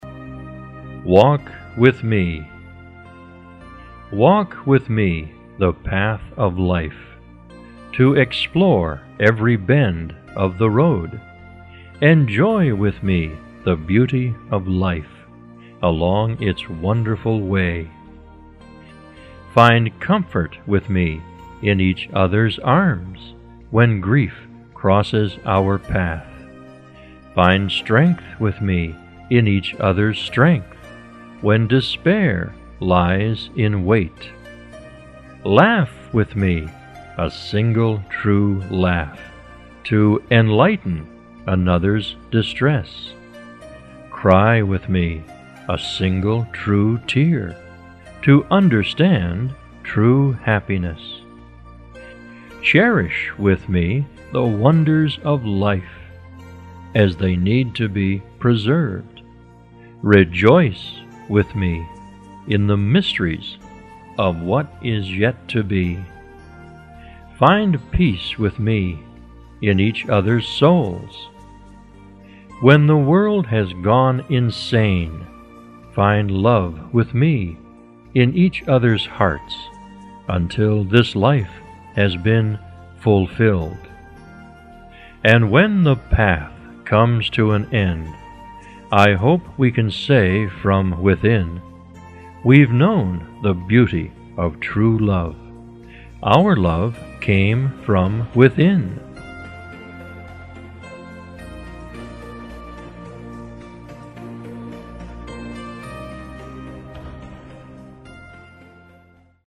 倾听一则故事，每则故事均由资深外教精心配音录制，深情的朗诵，搭配柔美乐音，让你在倾听如水般恬静流淌的英文的同时，放松心情、纾解压力。